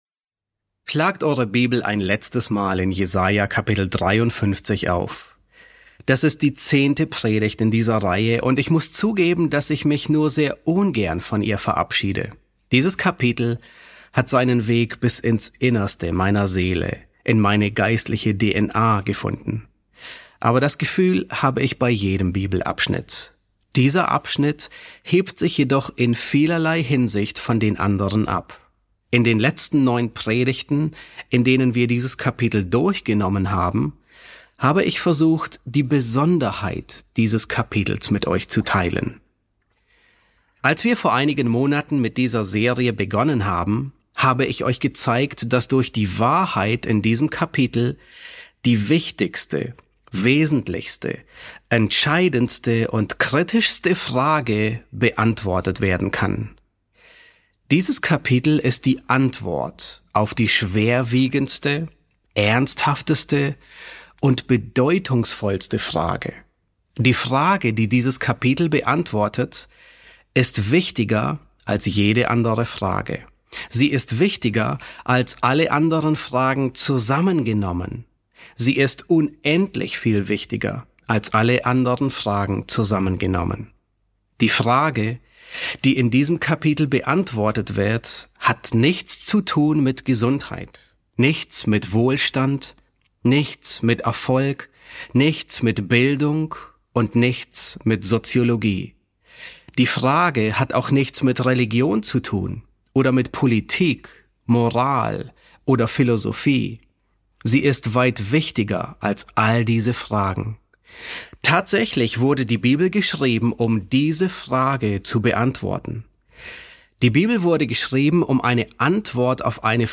S4 F10 | Der souveräne Knecht, Teil 2 ~ John MacArthur Predigten auf Deutsch Podcast